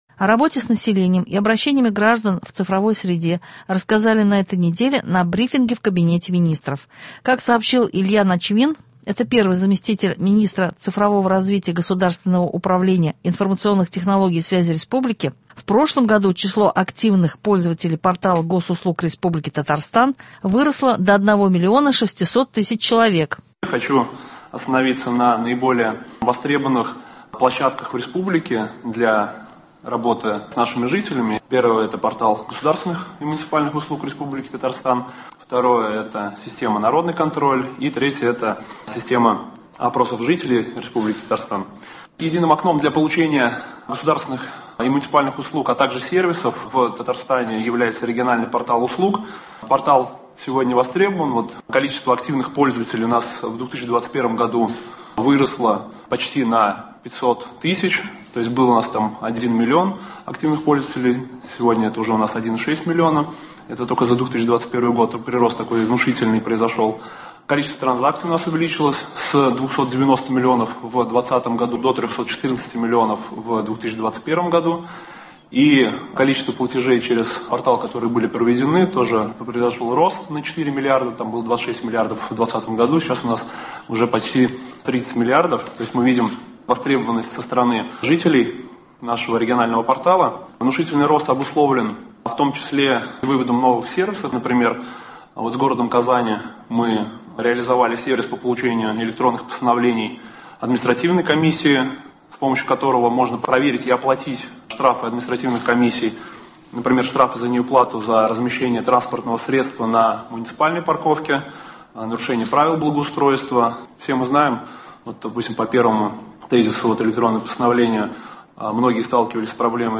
В преддверии Международного Женского дня предлагаем вашему вниманию записи из фондов радио – прозвучат голоса женщин, каждая из которых — образец высоких человеческих качеств.